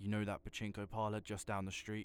Voice Lines